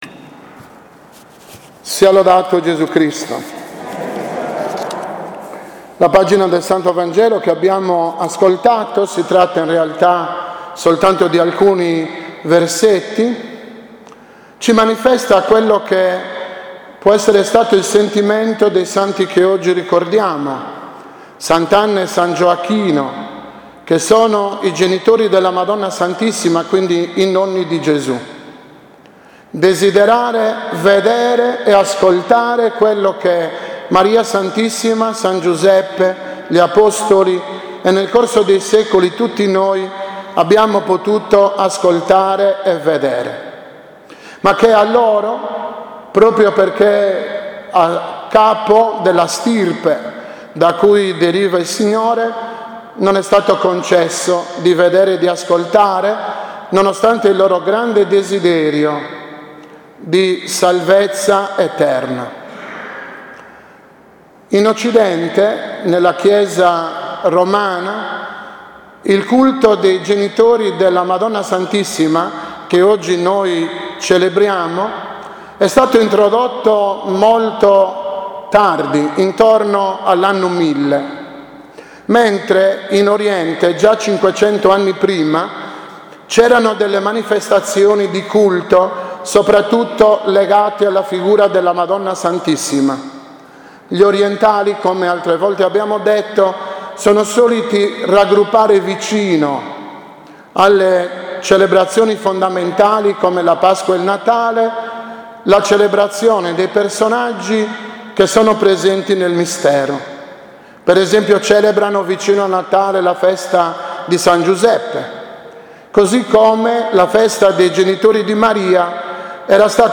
2018-OMELIA-NELLA-FESTA-DI-S.-ANNA.mp3